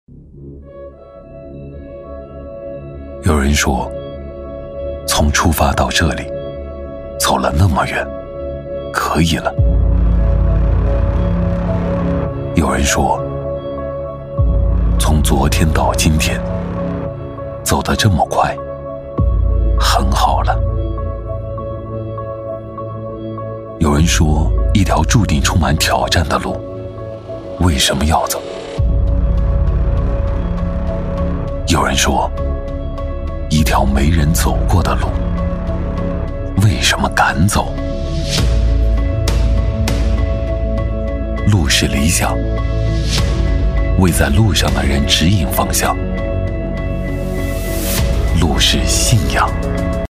男139-内心自白【有人说 道路 成熟低沉 大气】
男139-磁性沉稳 质感磁性
男139-内心自白【有人说  道路 成熟低沉 大气】.mp3